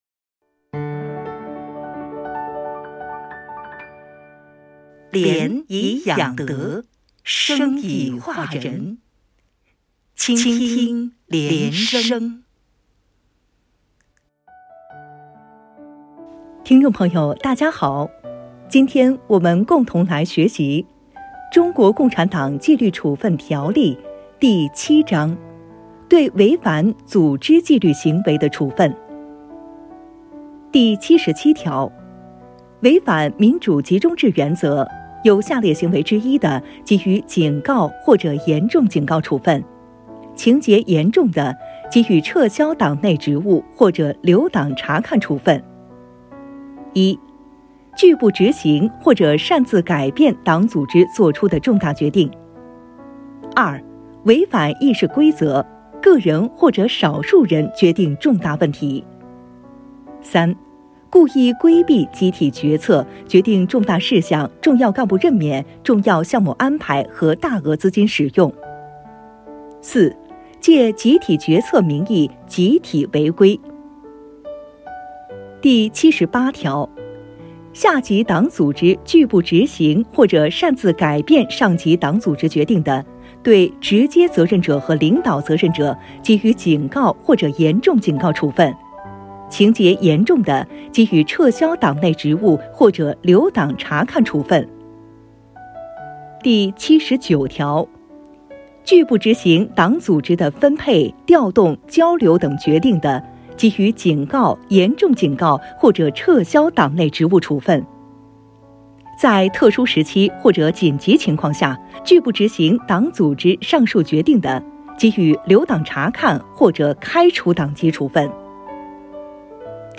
《中国共产党纪律处分条例》原文诵读系列音频